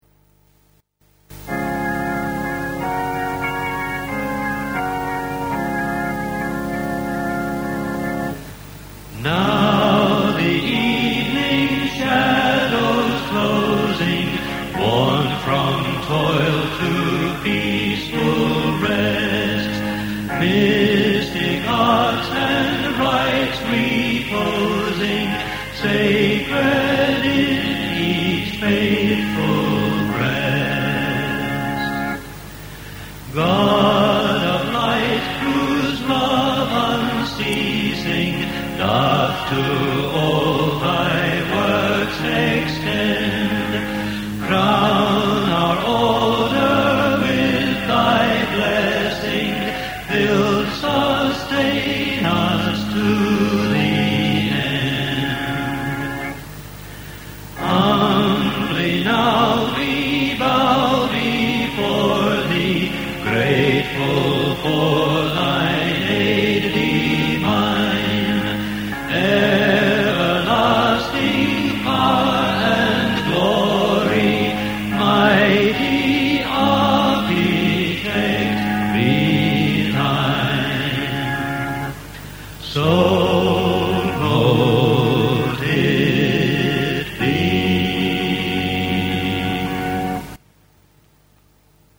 CLOSING HYMN - Now the evening shadows closing - (mp3 - 1.5Mb)
Organ.